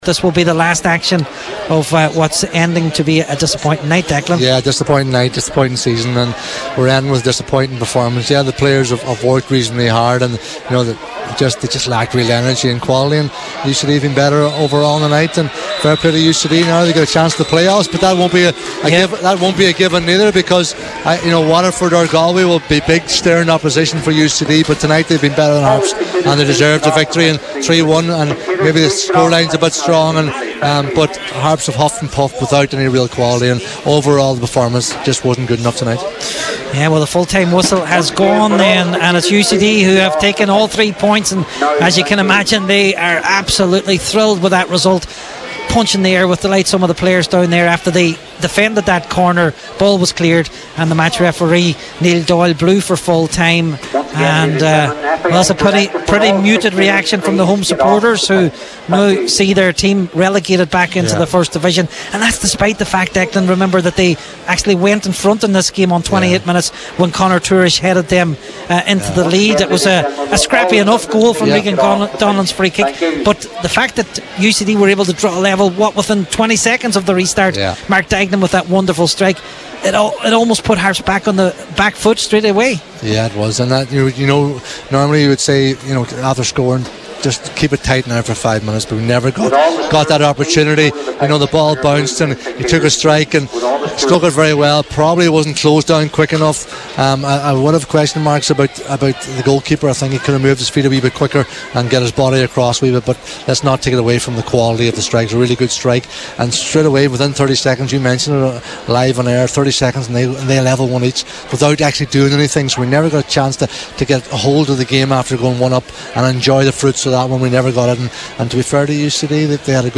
were on commentary at Finn Park for Highland Radio Sport: